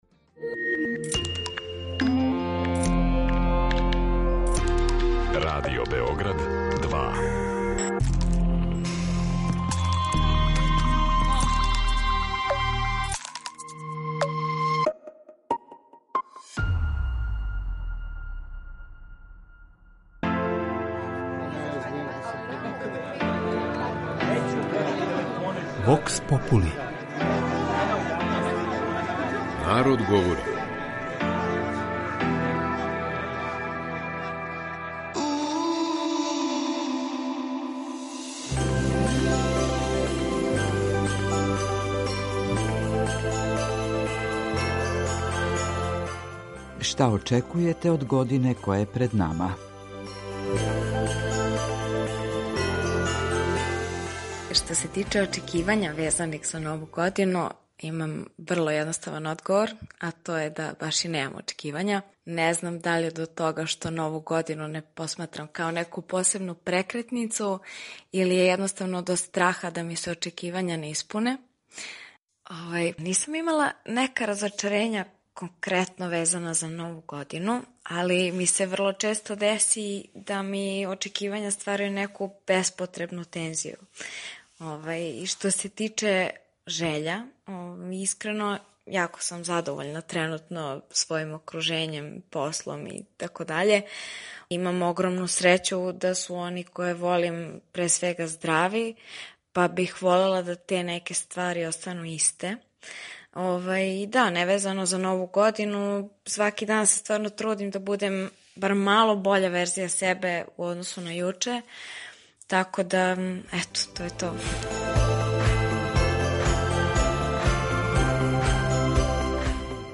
У данашњој емисији питали смо наше суграђане чему се надају и шта очекују од године која је пред нама.
Вокс попули